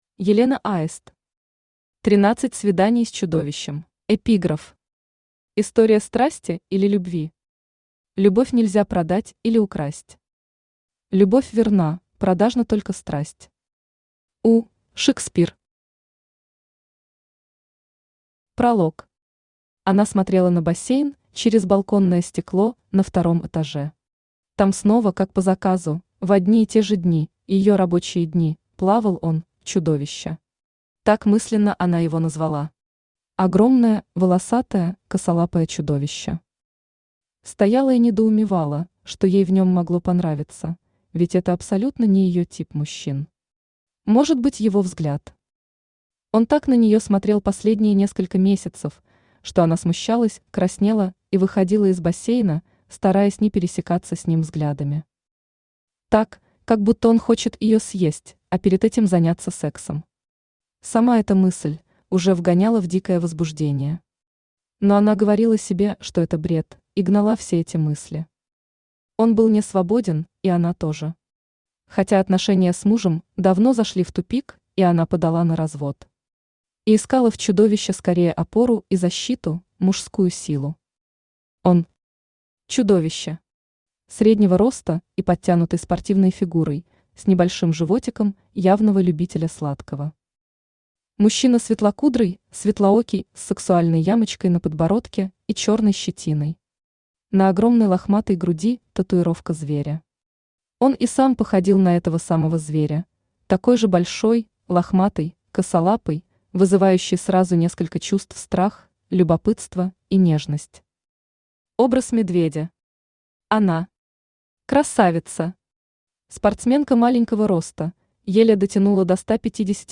Aудиокнига Тринадцать свиданий с Чудовищем Автор Елена Аист Читает аудиокнигу Авточтец ЛитРес. Прослушать и бесплатно скачать фрагмент аудиокниги